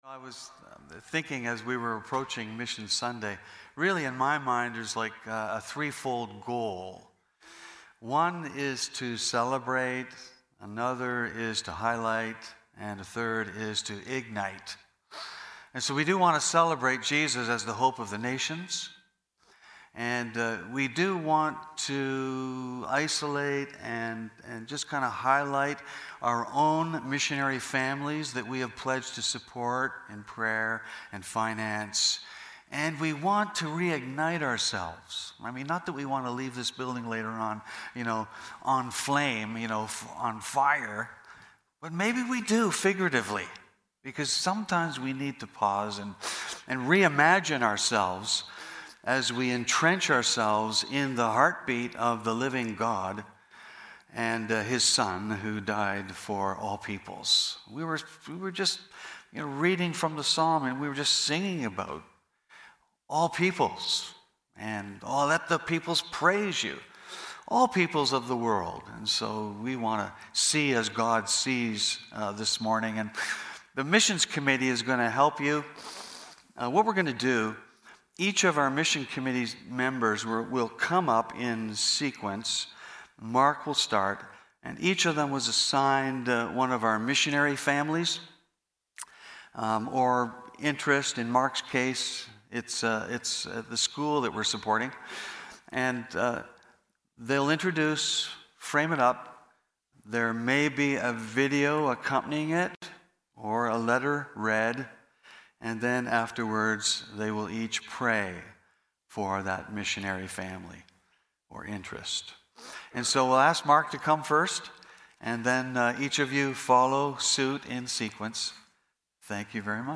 Preacher: Missions Committee